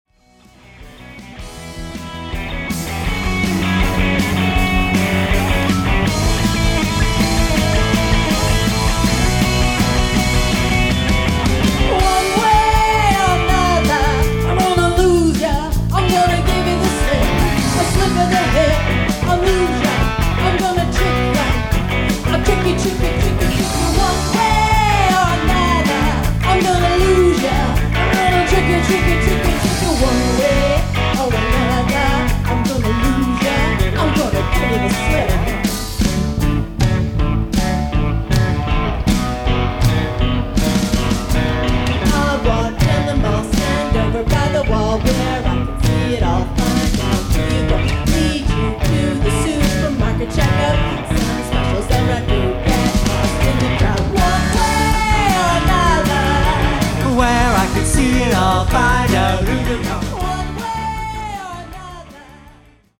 Lead Guitar / Vocals
Bass Guitar / Vocals